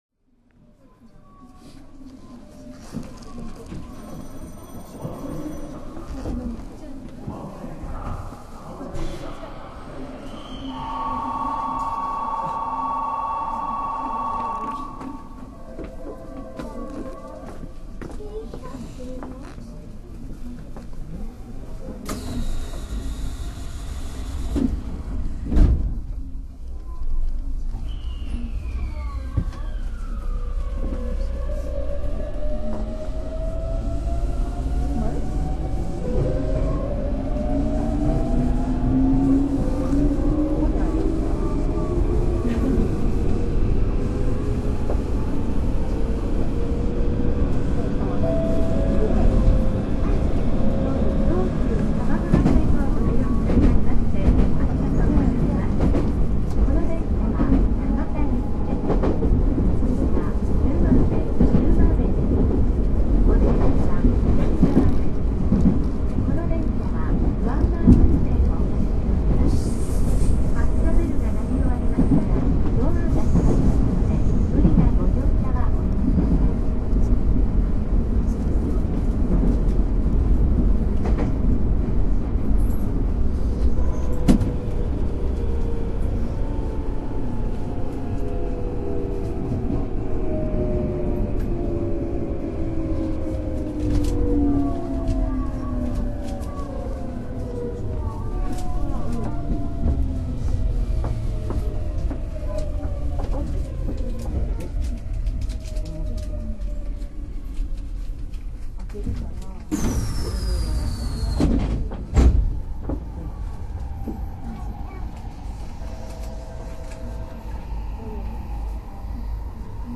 東洋電機製の後期ＧＴＯ−ＶＶＶＦインバータを装備します。
ＰＡＲＴ３　多摩川〜鵜の木 （１．６７ＭＢ）